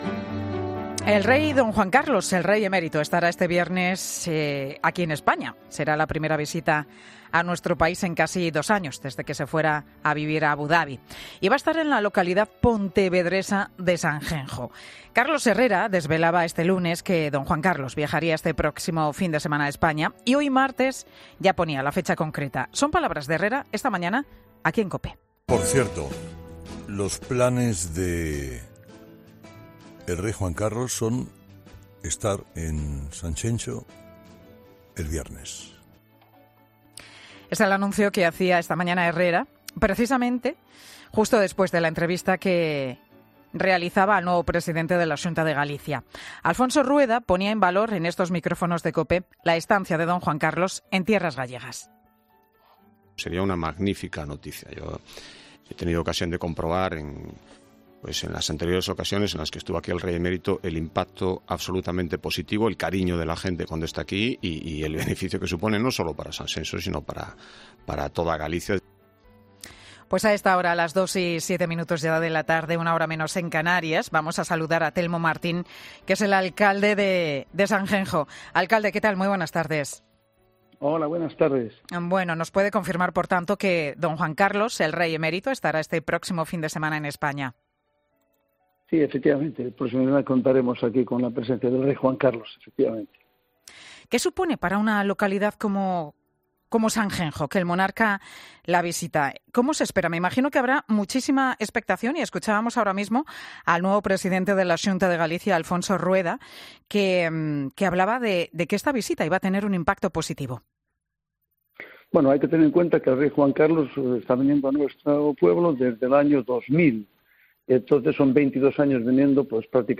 En Mediodía COPE, hemos hablado con Telmo Martín, alcalde de Sanxenxo, que ha celebrado la noticia de la visita del Rey a la localidad gallega: “Hay que tener en cuenta que el Rey Juan Carlos lleva 22 años viniendo asiduamente, más en los últimos años. Estamos encantados porque hay un Sanxenxo antes y después de estar viniendo aquí el Rey emérito. Le tenemos un cariño enorme”.